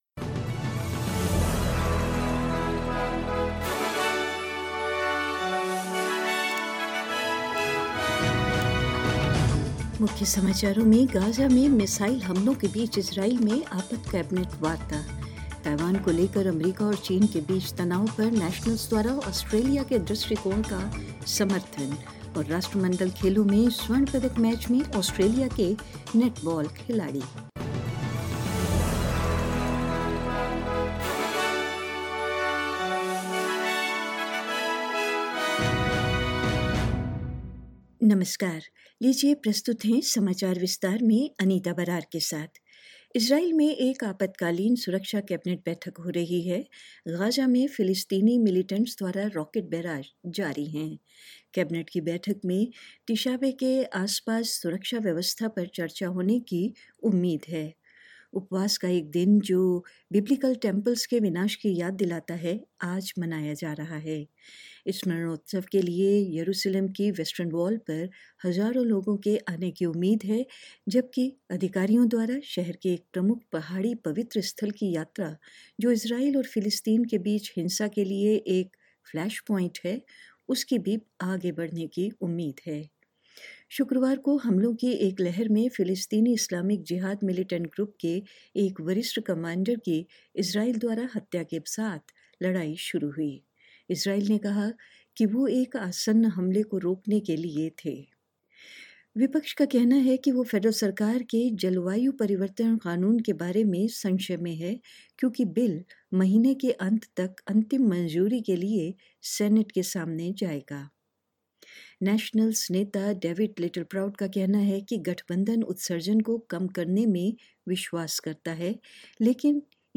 In this latest SBS Hindi bulletin: Israel vows to stand up to violence as cross-border clashes in Gaza continue: The Nationals endorse Australia's response to tensions between the U-S and China over Taiwan; Australian netballers reach the gold medal match at the Commonwealth Games and more.